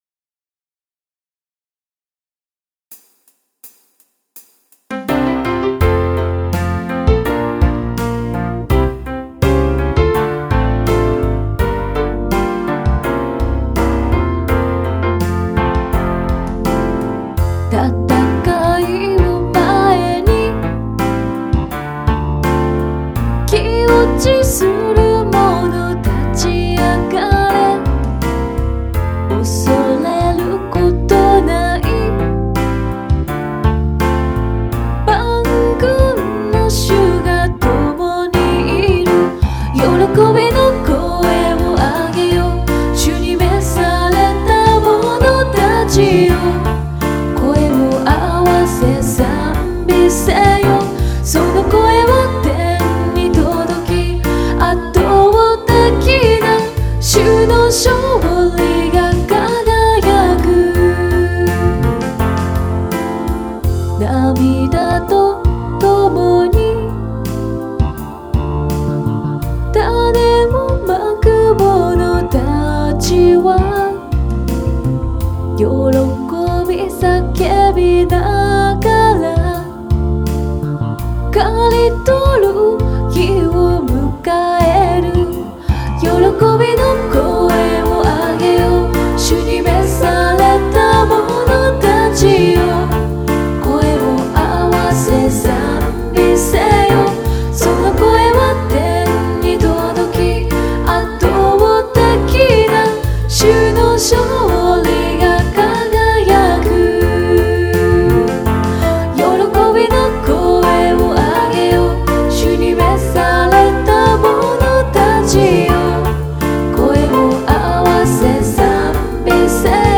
オリジナルゴスペルソング♪
〜　Japanese Original Gospel　〜